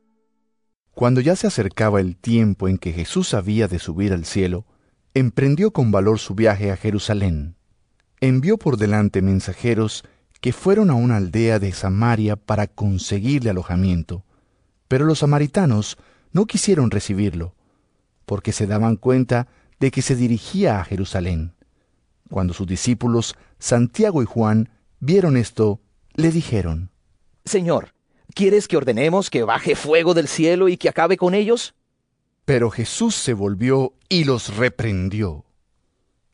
Lc 9 51-56 EVANGELIO EN AUDIO